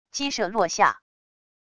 激射落下wav音频